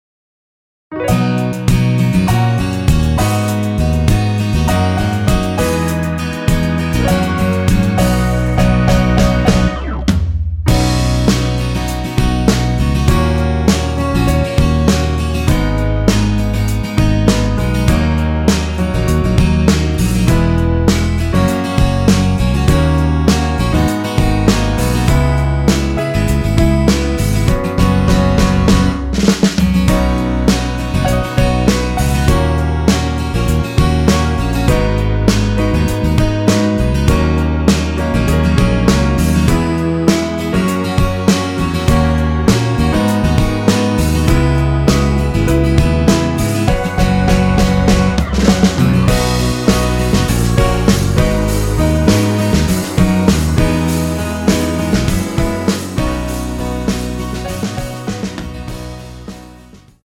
MR입니다.
앨범 | O.S.T
앞부분30초, 뒷부분30초씩 편집해서 올려 드리고 있습니다.
중간에 음이 끈어지고 다시 나오는 이유는